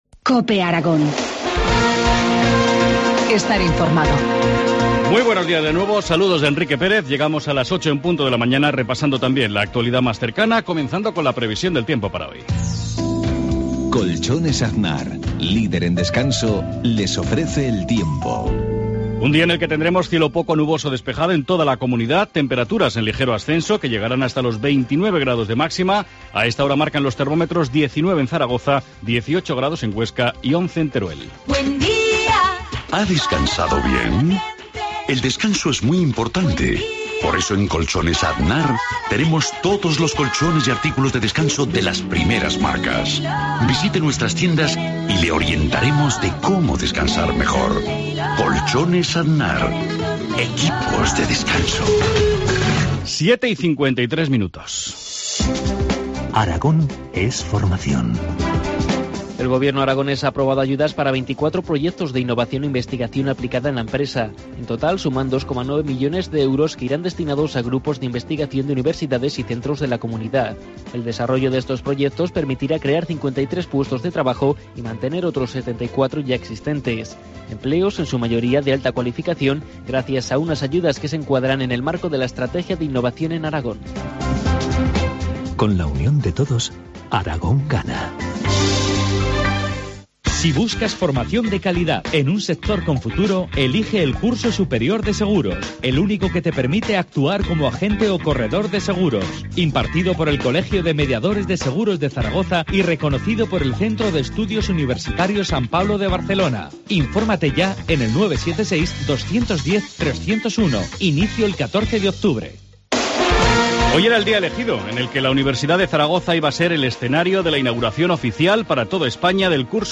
Informativo matinal, lunes 23 de septiembre, 7.25 horas